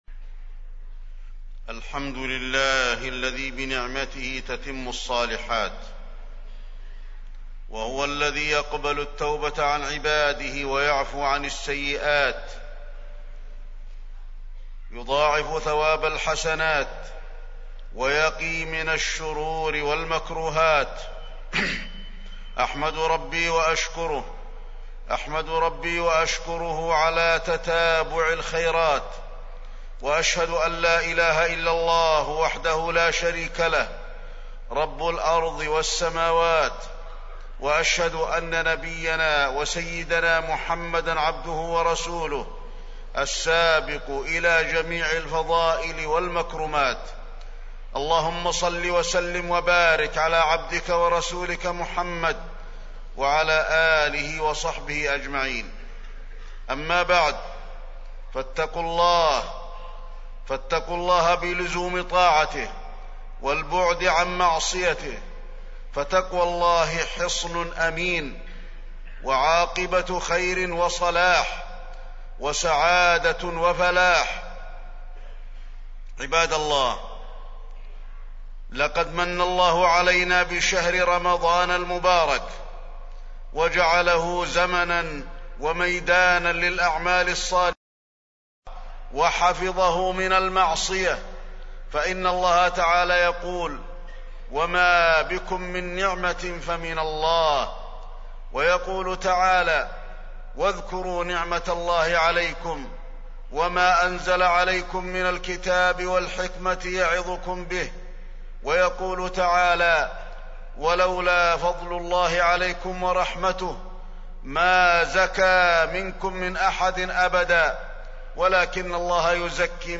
تاريخ النشر ٢٥ رمضان ١٤٢٦ هـ المكان: المسجد النبوي الشيخ: فضيلة الشيخ د. علي بن عبدالرحمن الحذيفي فضيلة الشيخ د. علي بن عبدالرحمن الحذيفي اغتنام العشر الأواخر من رمضان